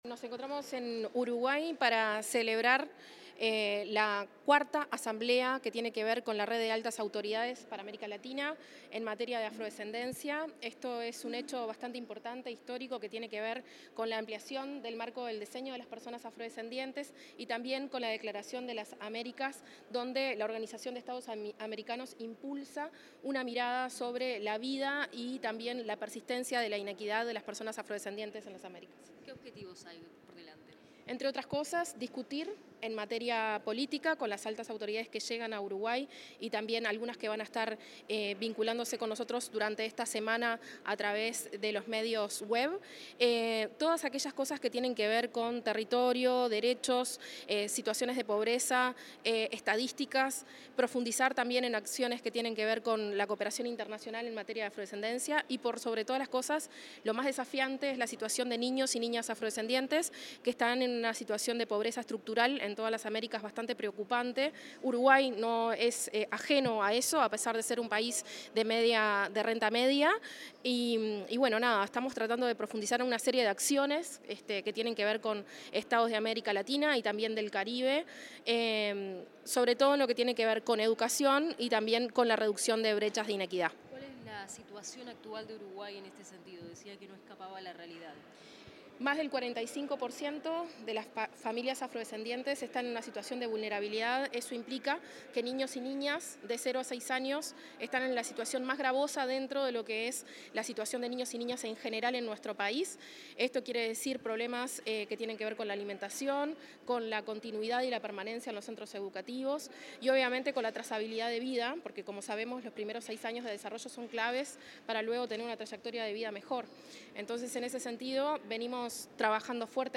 Declaraciones de la directora de Promoción de Políticas Públicas para Afrodescendientes, Leticia Rodríguez
Declaraciones de la directora de Promoción de Políticas Públicas para Afrodescendientes, Leticia Rodríguez 24/11/2025 Compartir Facebook X Copiar enlace WhatsApp LinkedIn En la apertura de la IV Reunión Interamericana Plenaria Ordinaria de Altas Autoridades de Política para Población Afrodescendiente, la directora de Promoción de Políticas Públicas para Afrodescendientes, Leticia Rodríguez, realizó declaraciones a la prensa.